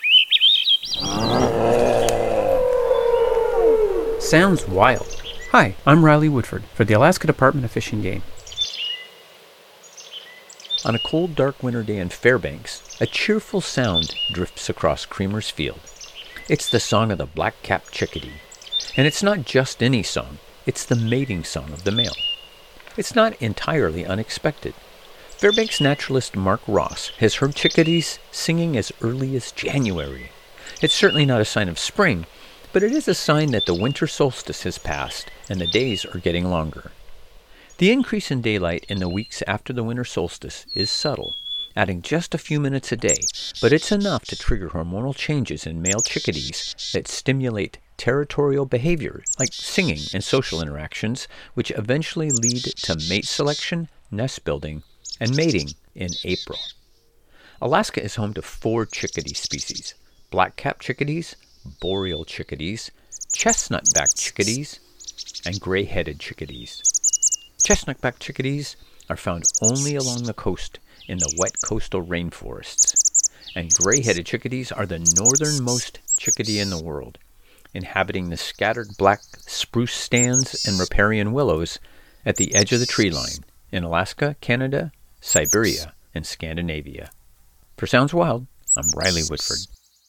It's the song of a black capped chickadee. And it's not just any song, it's the mating song of the male.
winter-singing-chickadees.mp3